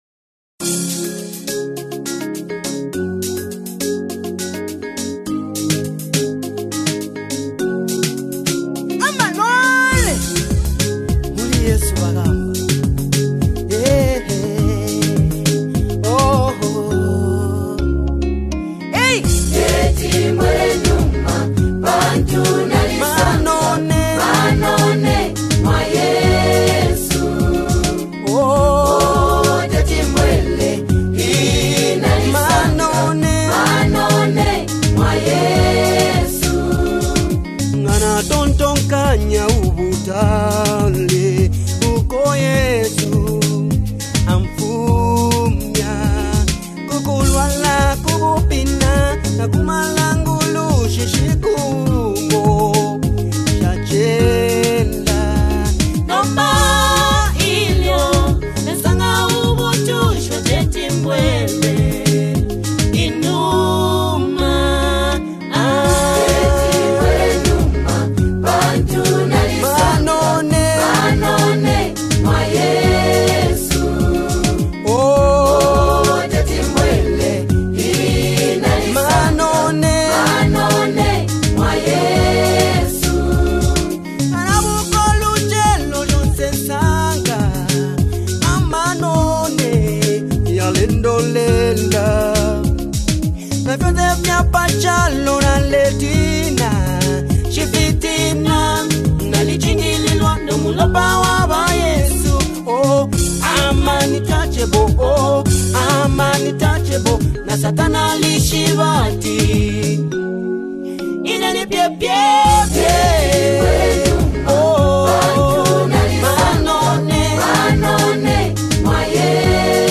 Zambian Gospel Music